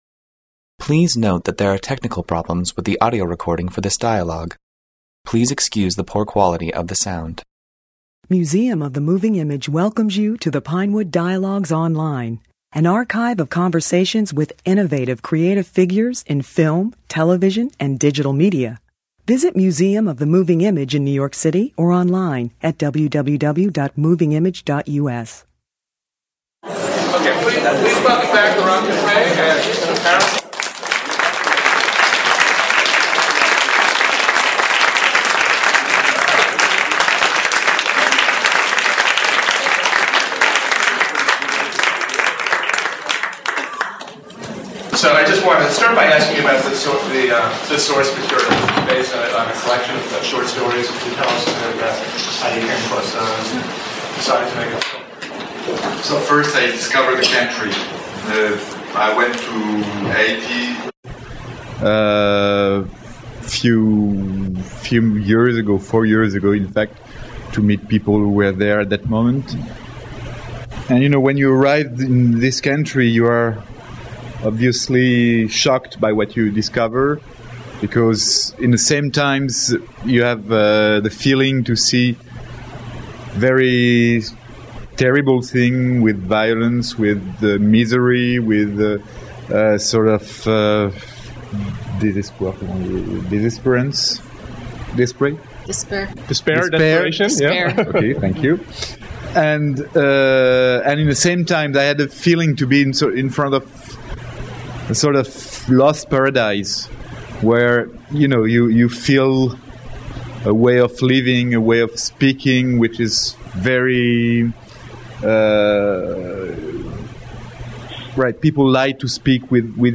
Laurent Cantet, the French filmmaker who directed the Cannes Film Festival's 2008 Palme d'Or winner The Class, discussed his film Heading South (2005) at the Museum of the Moving Image, along with Karen Young, one of the lead actresses. Set in Haiti, the film follows a group of female tourists who travel to the country seeking adventure and some form of romance.